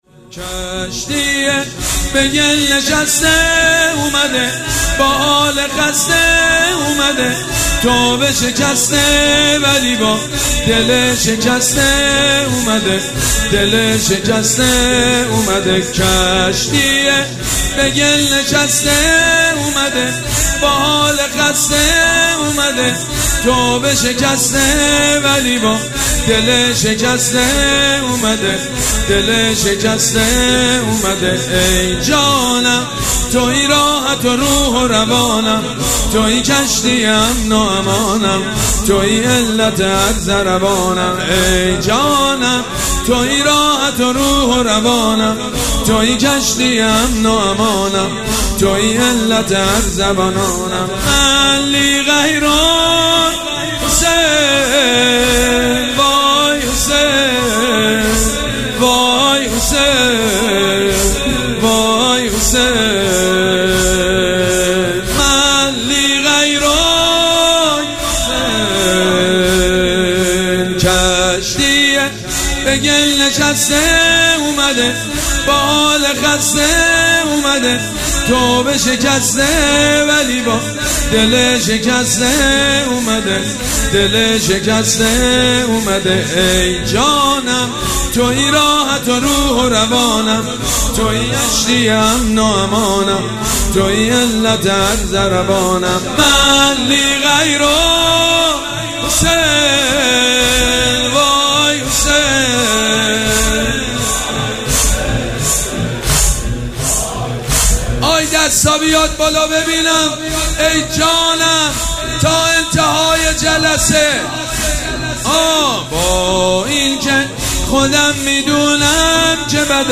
شب سوم مراسم عزاداری اربعین حسینی ۱۴۴۷
مداح
حاج سید مجید بنی فاطمه